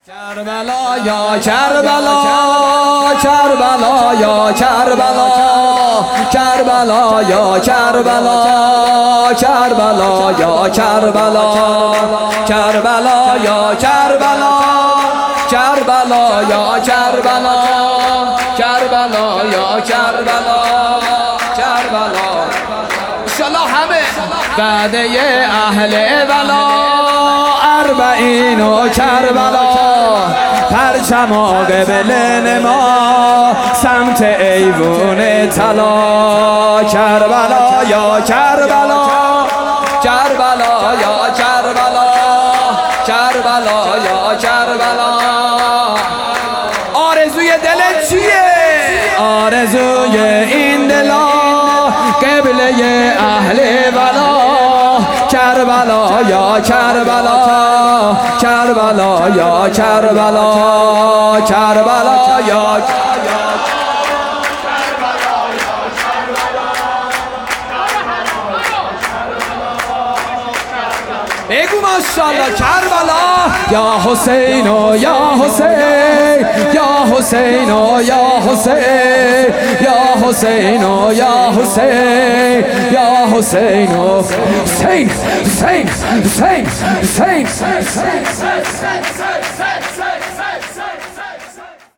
حسینیه بیت النبی
میلاد پیامبر اکرم صلی الله علیه و آله